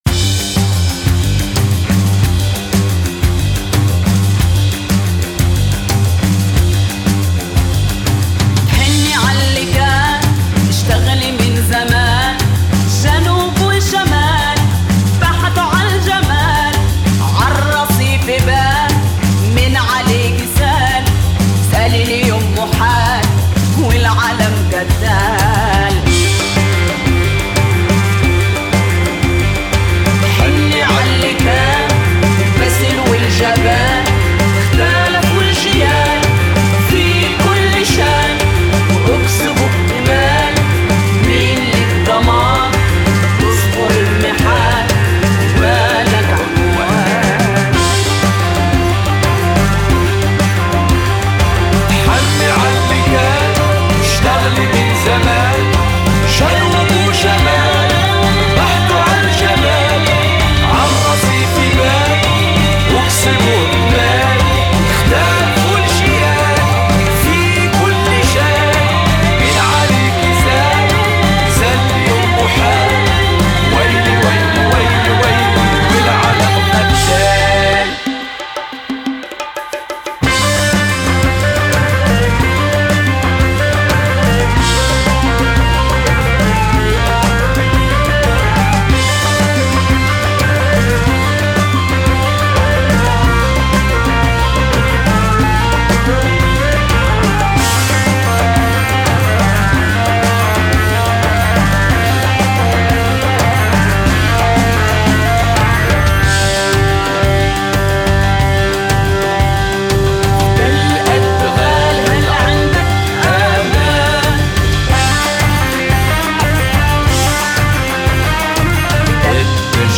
BarbésCore